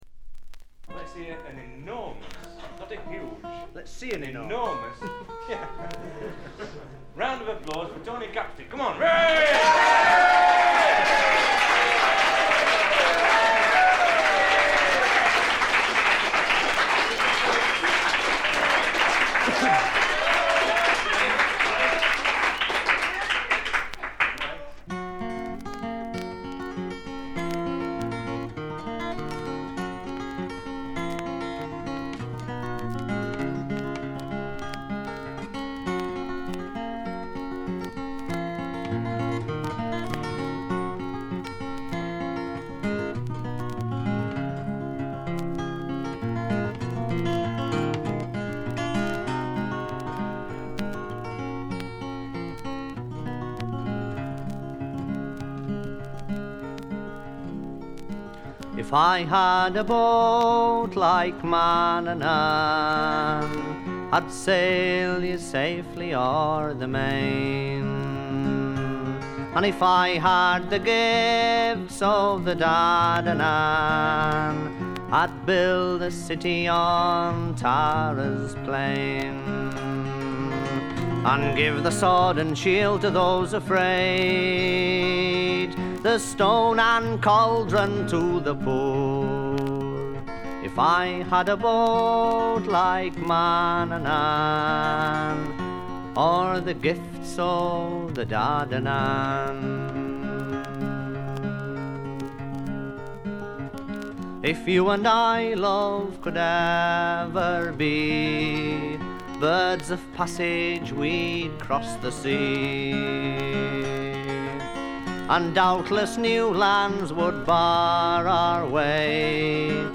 バックグラウンドノイズ、チリプチはそこそこ出ますが鑑賞を妨げるほどのノイズはありません。
自身のギターの弾き語りで全13曲。
試聴曲は現品からの取り込み音源です。
guitar, vocals